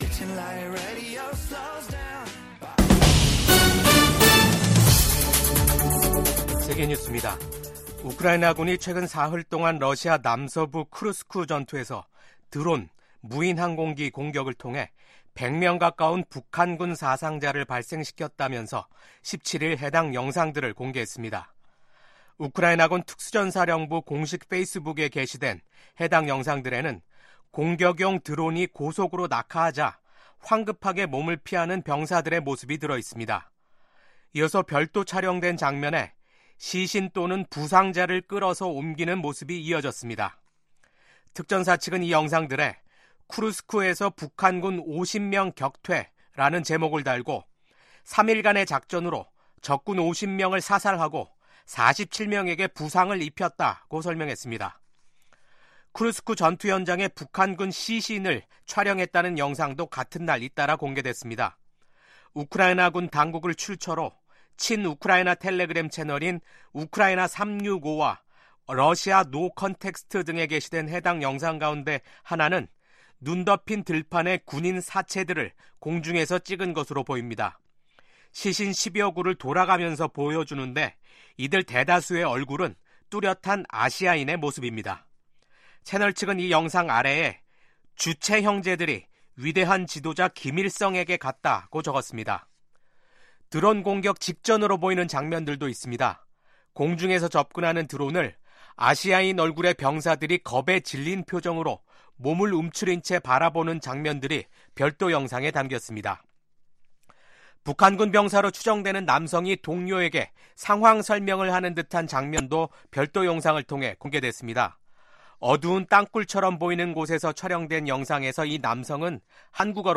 VOA 한국어 아침 뉴스 프로그램 '워싱턴 뉴스 광장'입니다. 러시아에 파병된 북한군에서 수백 명의 사상자가 발생했다고 미군 고위 당국자가 밝혔습니다. 미국 국무부는 한국 대통령 탄핵소추안 통과와 관련해 한국 헌법 절차가 취지대로 작동하고 있다고 평가했습니다.